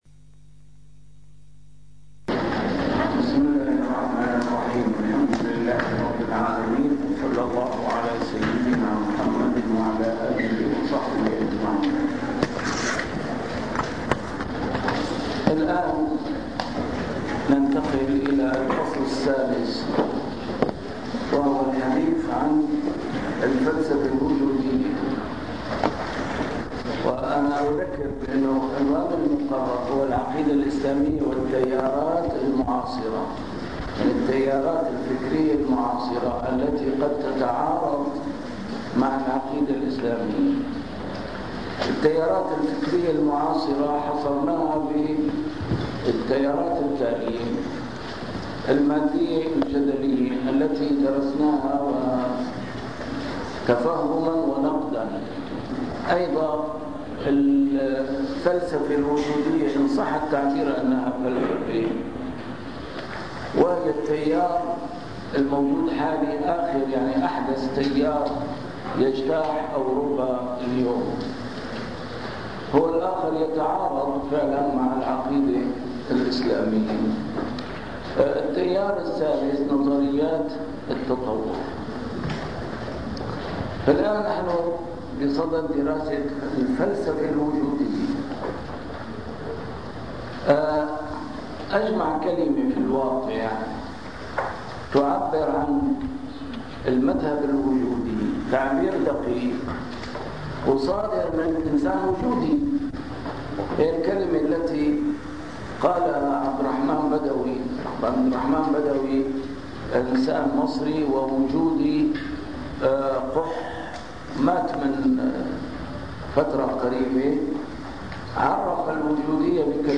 المحاضرة الخامسة: المذهب الوجودي ونقده 1